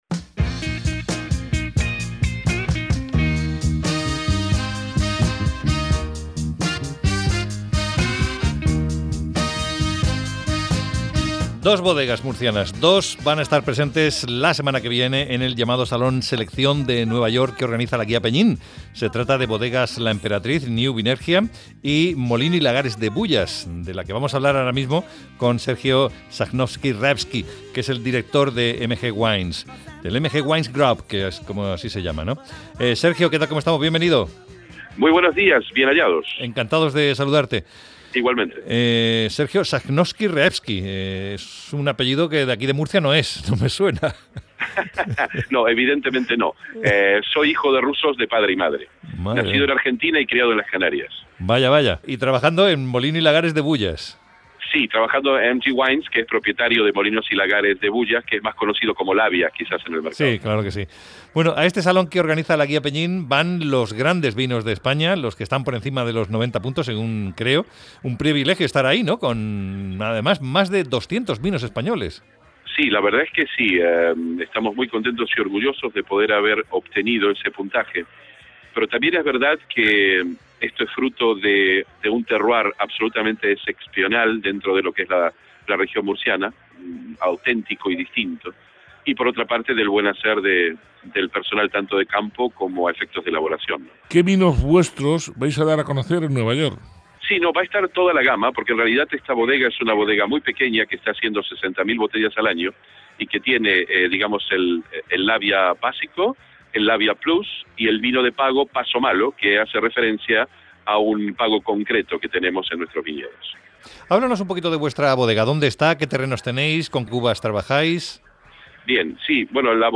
fue entrevistado en el programa